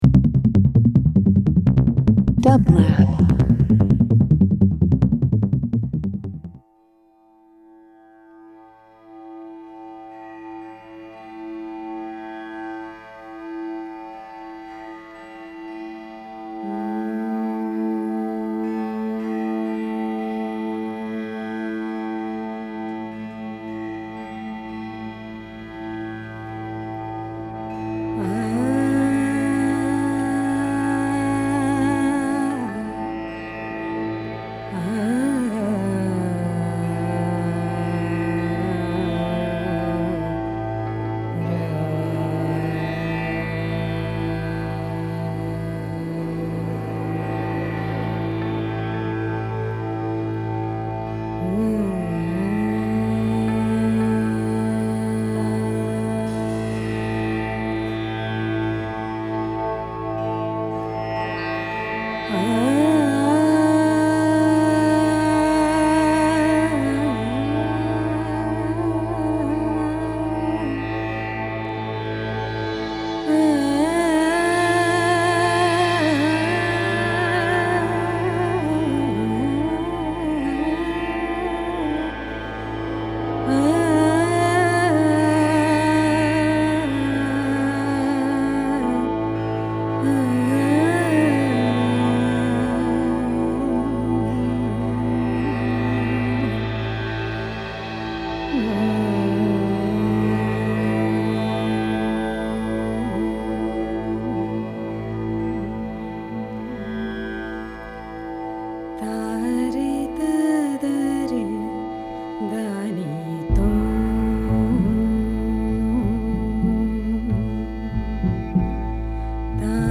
Indian International Traditional